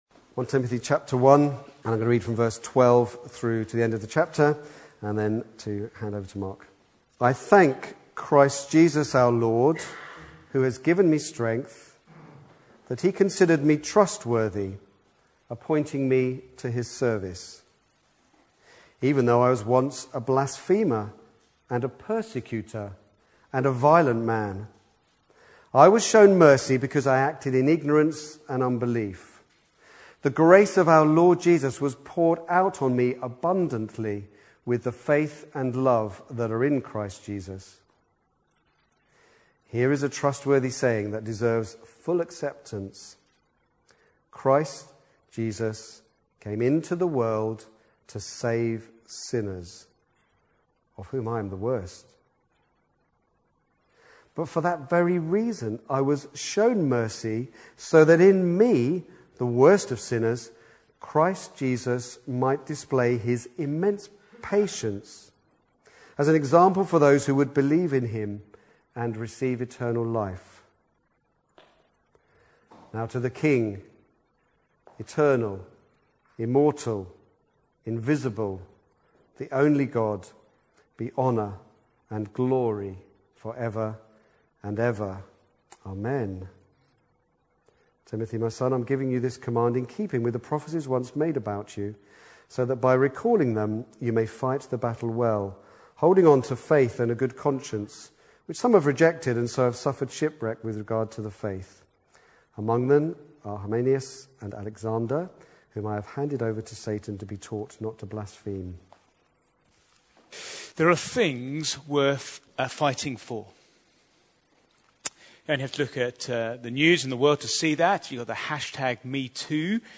Back to Sermons the beautiful gospel